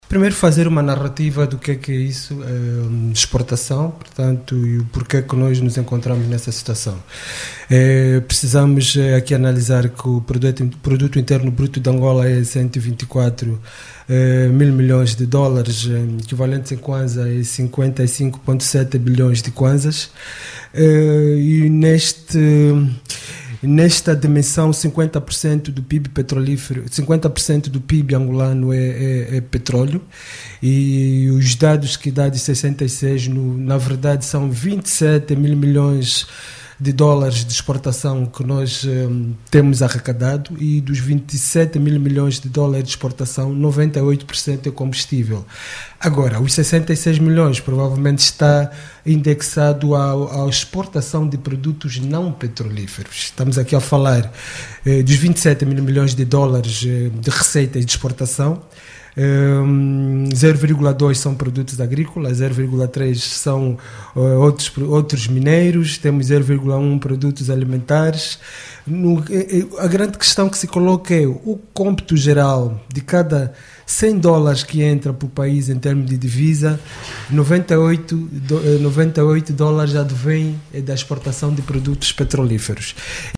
Exportações angolanas em debate na RÁDIO NOVA